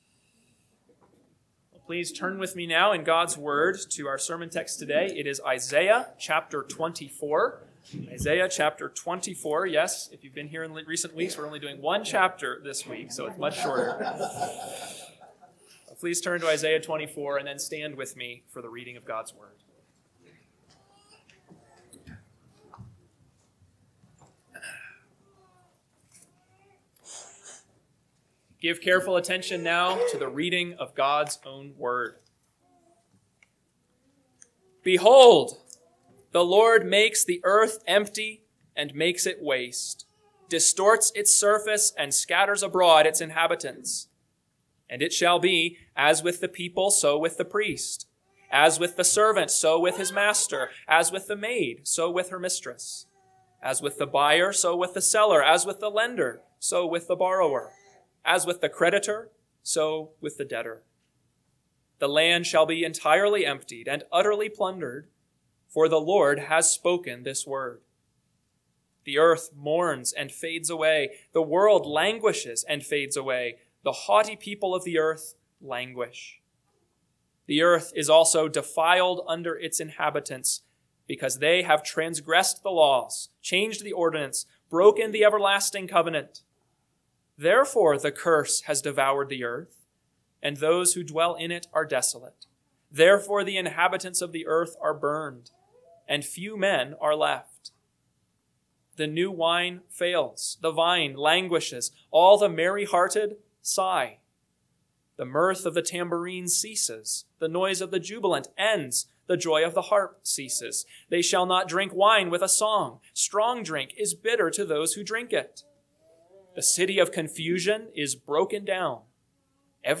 AM Sermon – 3/22/2026 – Isaiah 24 – Northwoods Sermons